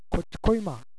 能登弁とはいえ私が能登に住んでいたころ使っていた方言です。
地域によってはまったく違った言い方の場合もあります。